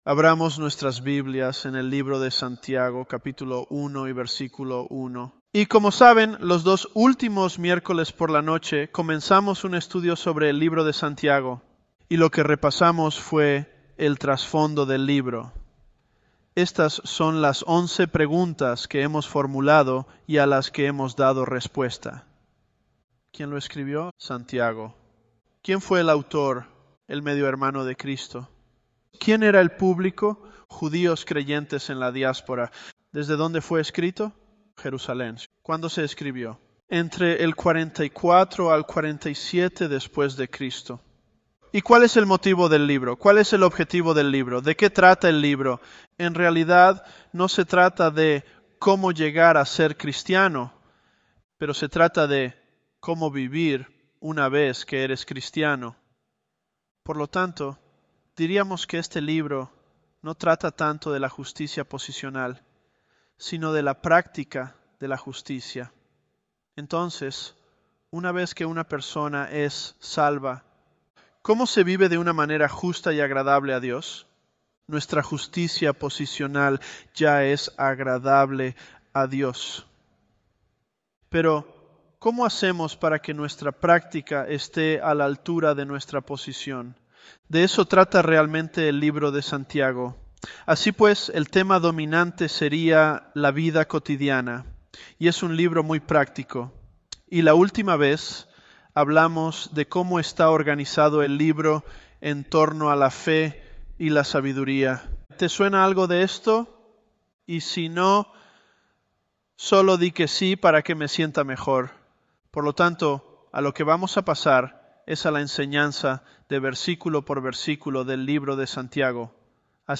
Elevenlabs_James003.mp3